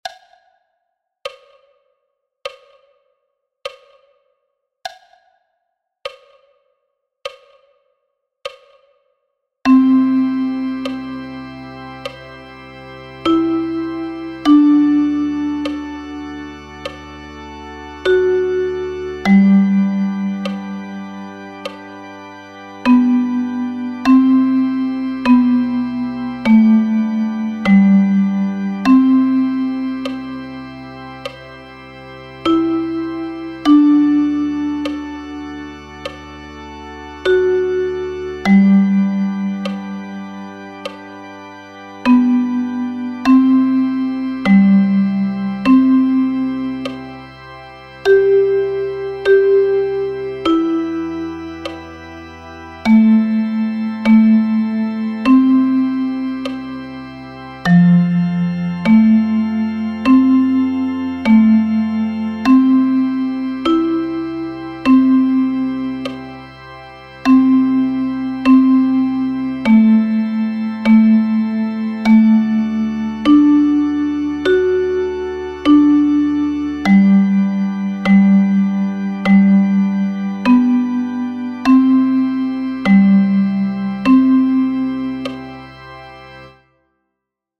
12 Evergreens – Duette für Sopran- & Altblockflöte